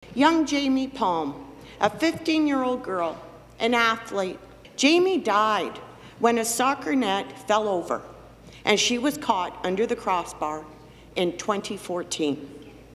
She spoke of a similar tragedy in her riding.